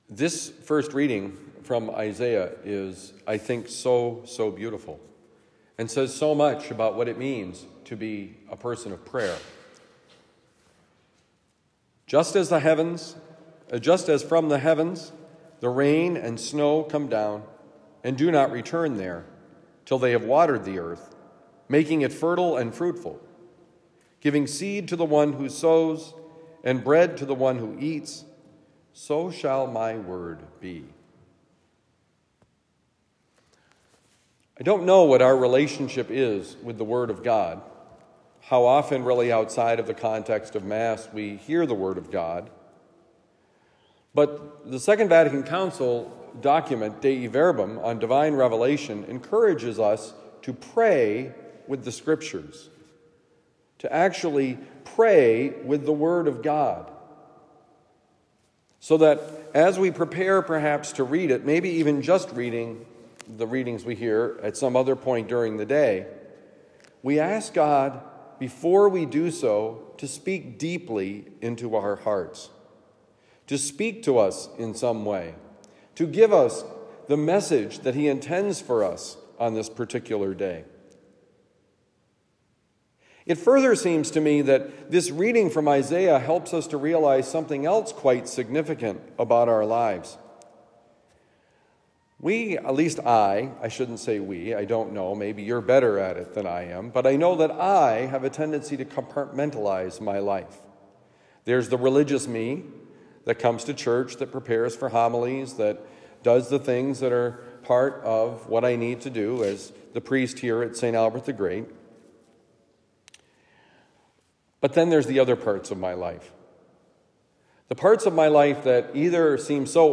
Where is God?: Homily for Tuesday, February 20, 2024